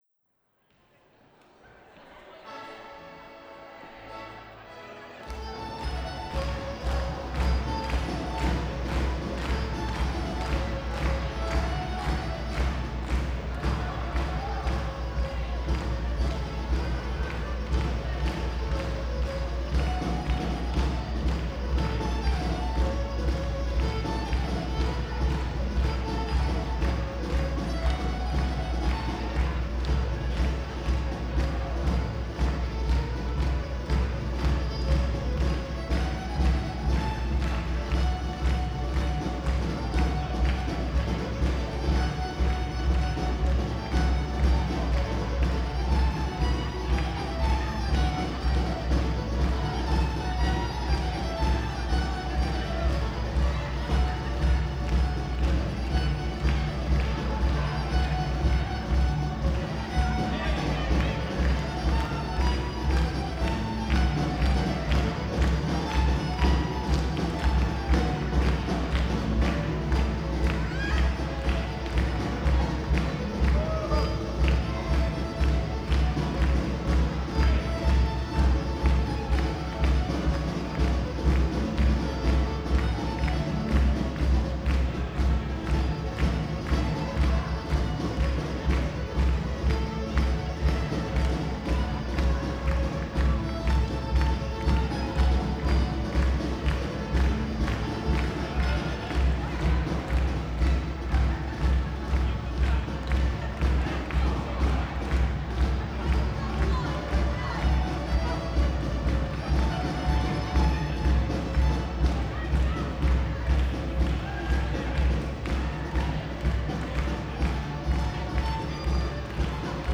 There was a "Rock & Roll Ceilidh" held in Oxford Town Hall to celebrate new facilities for cancer research in the university. There was no caller during the dances - we practised beforehand. The dancers pass under the microphones, and the music is amplified.
Ambisonic
Ambisonic order: H (3 ch) 1st order horizontal
Recording device: Motu Traveler
Microphone name: home made
Array type: Native B-format
Capsule type: AKG Blue Line